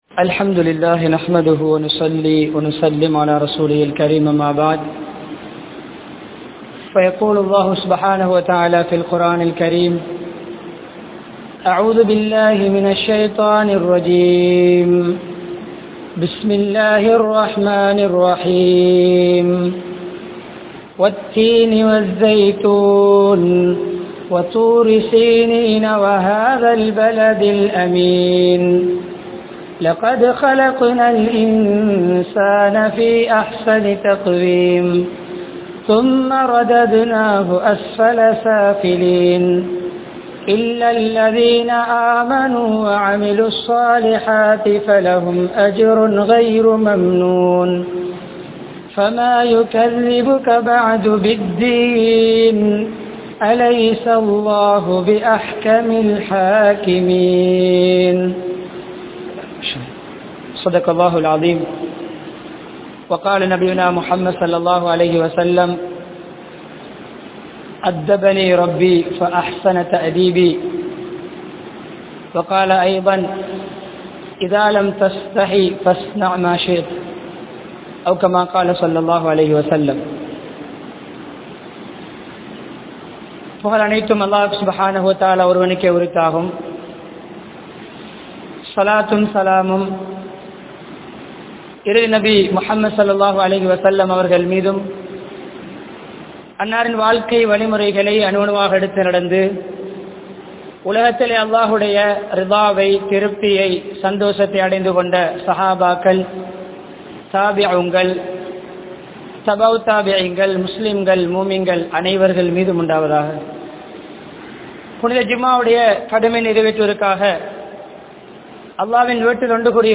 Aadai,Alangaaraththin Olukkangal (ஆடை அலங்காரத்தின் ஒழுக்கங்கள்) | Audio Bayans | All Ceylon Muslim Youth Community | Addalaichenai
Mannar, Uppukkulam, Al Azhar Jumua Masjidh